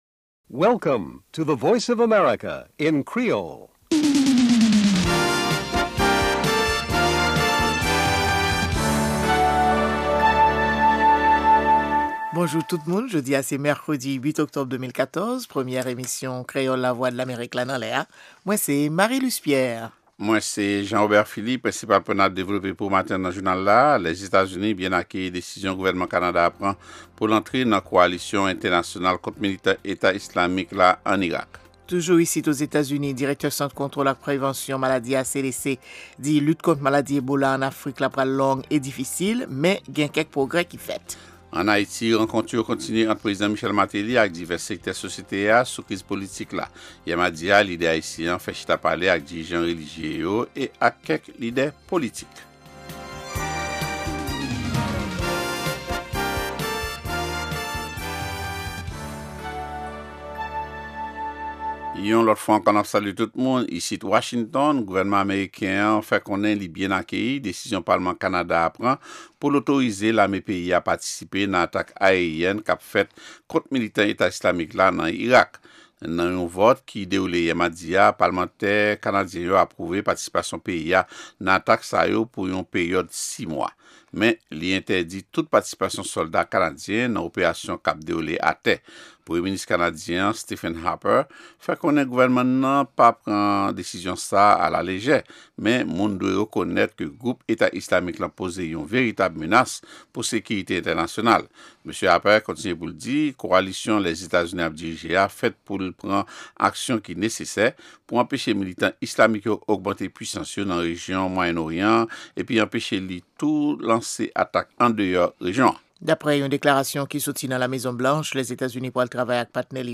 Se premye pwogram jounen an ki gen ladan dènye nouvèl sou Lèzetazini, Ayiti ak rès mond la. Pami segman yo genyen espò, dyasporama ak editoryal la.